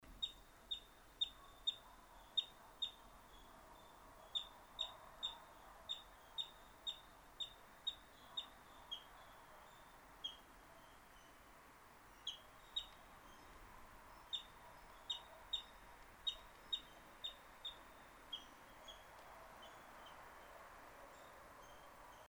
نام فارسی : یلوه آبی
نام انگلیسی : Water Rail